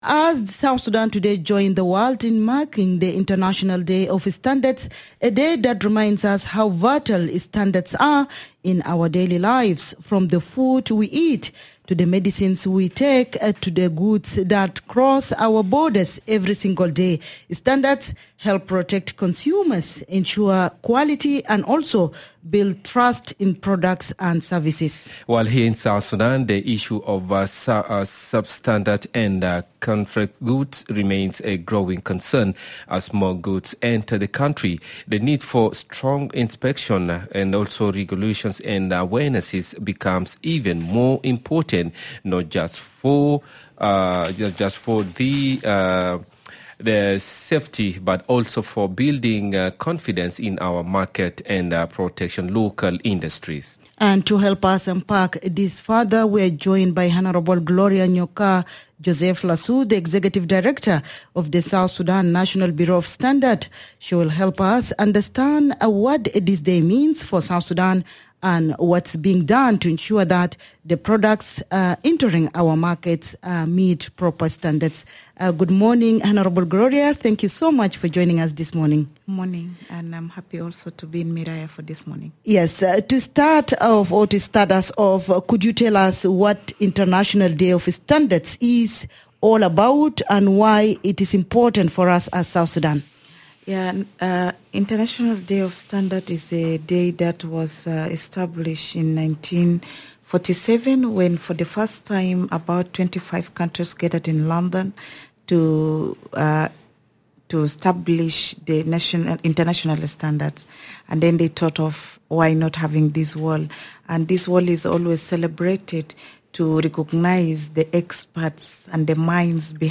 Headliner Embed Embed code See more options Share Facebook X Subscribe Guest: Hon. Gloria Nyoka Joseph Lasu, the Executive Director of the South Sudan National Bureau of Standards Share Facebook X Subscribe Next MBS.